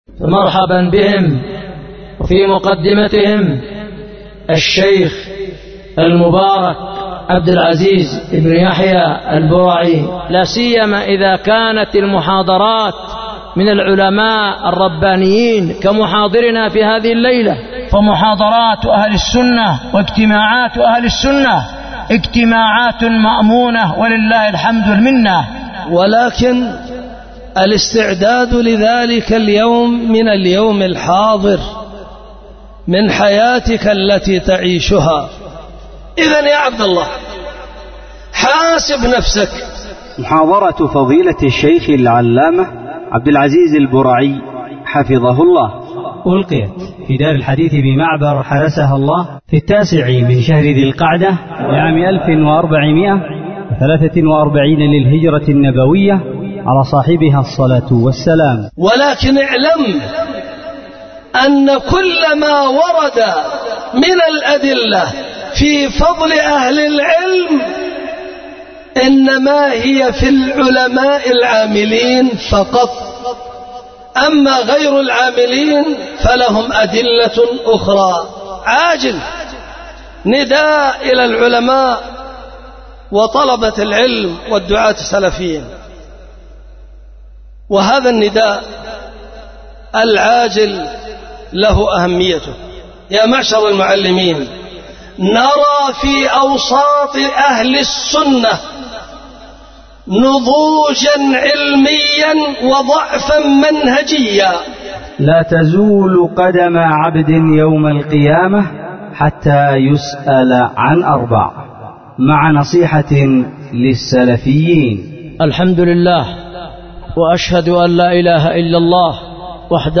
محاضرة بدار الحديث بمعبر